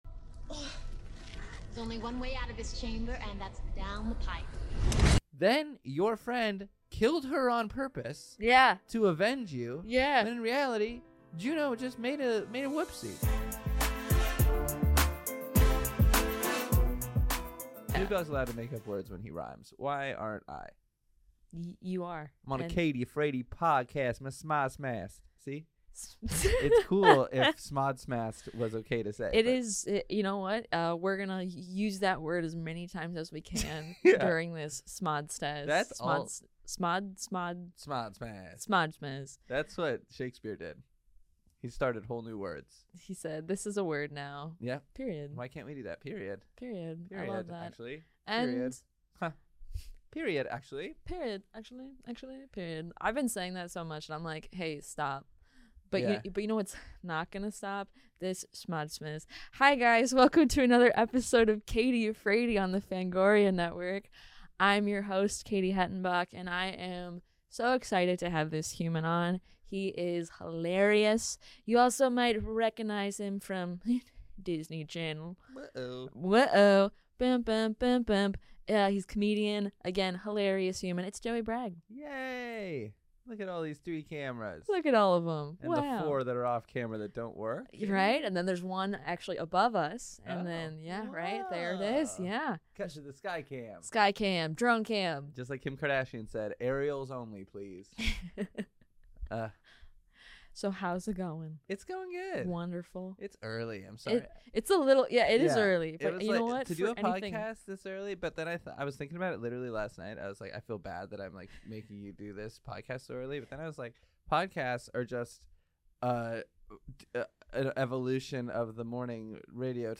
talks with comedians, actors, and filmmakers about horror movies!